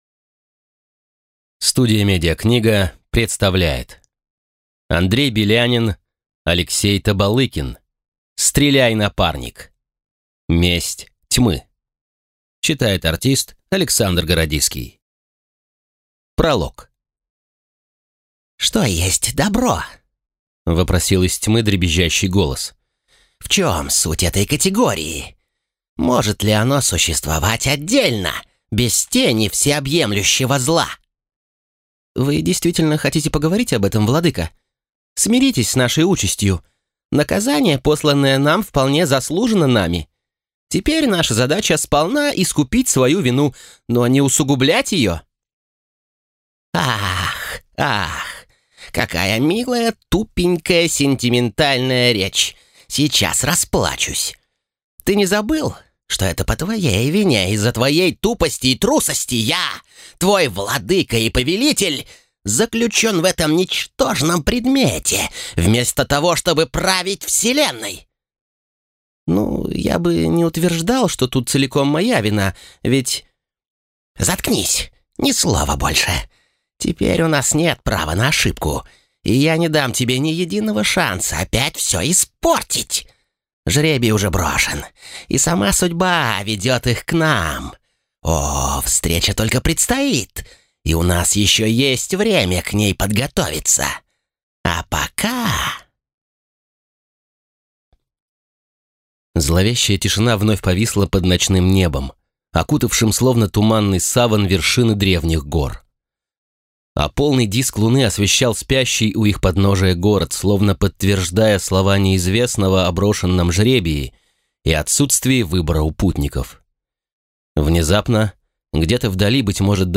Аудиокнига Стреляй, напарник! Месть тьмы | Библиотека аудиокниг